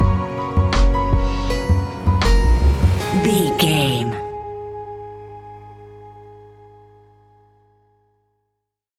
Ionian/Major
G♭
chilled
laid back
Lounge
sparse
new age
chilled electronica
ambient
atmospheric
morphing
instrumentals